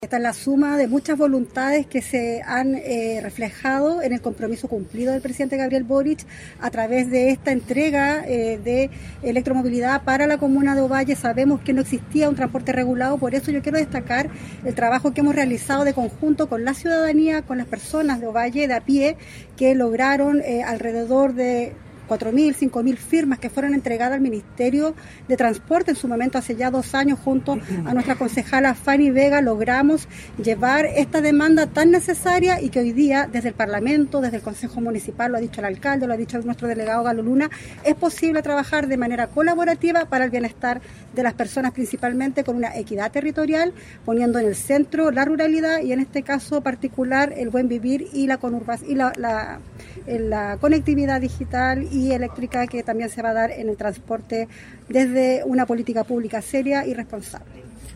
ELECTROMOVILIDAD-OVALLE-Diputada-Nathalie-Castillo.mp3